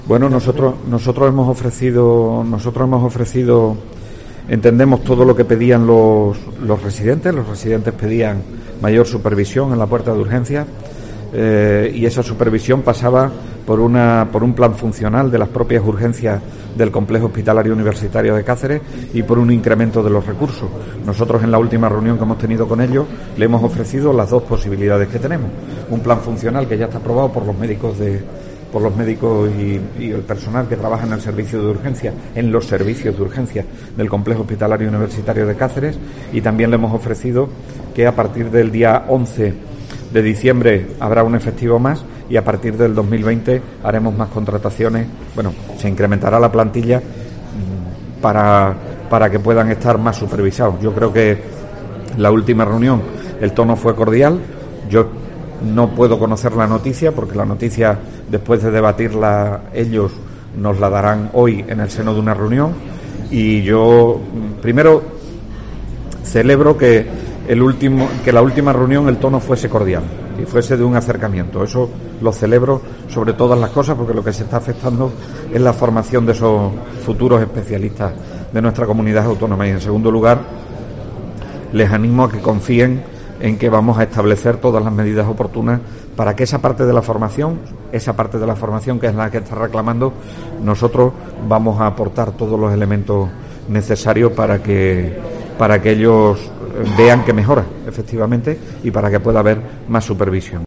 El vicepresidente segundo y consejero de Sanidad y Servicios Sociales, ha realizado estas declaraciones en el marco de la inauguración de las II Jornadas de la Sociedad Extremeña de Enfermedades Raras (SEDER), que se celebran en estos días en el Colegio Oficial de Médicos.